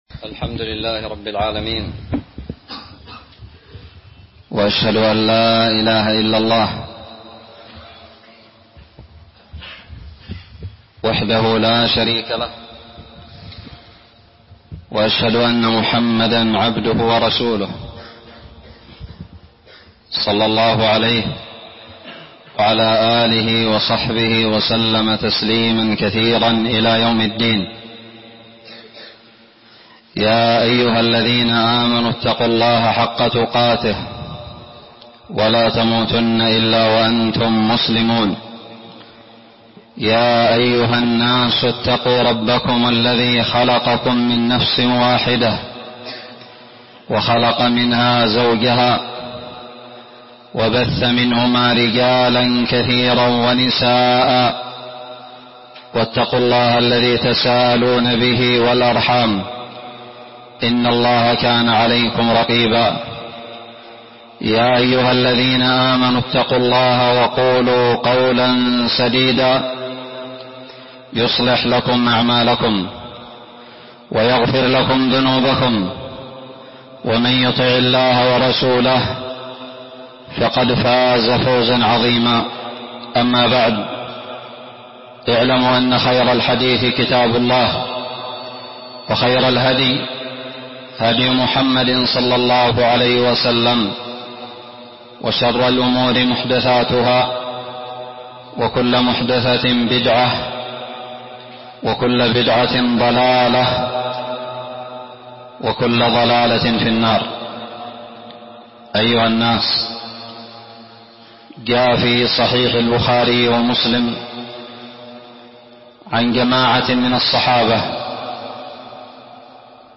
276 - خطبة الكسوف1441هـ
ألقيت بدار الحديث السلفية للعلوم الشرعية بالضالع في عام 1441هــ